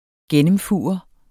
Udtale [ ˈgεnəmˌfuˀʌ ]